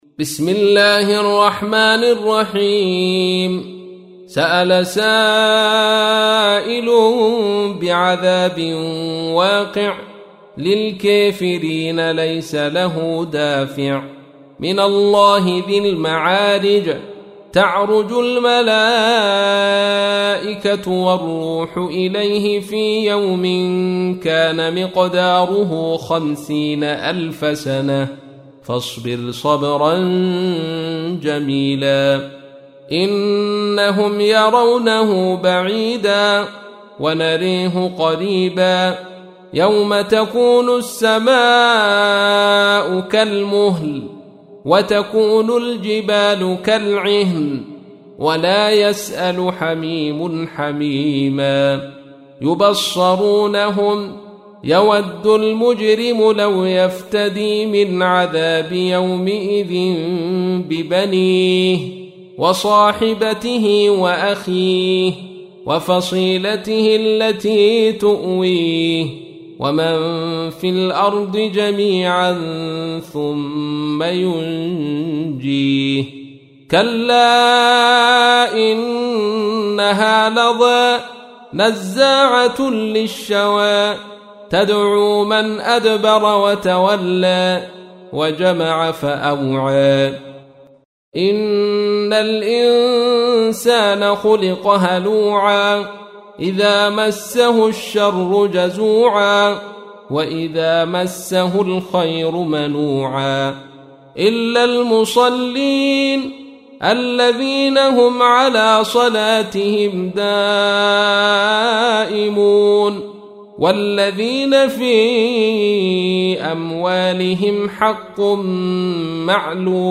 تحميل : 70. سورة المعارج / القارئ عبد الرشيد صوفي / القرآن الكريم / موقع يا حسين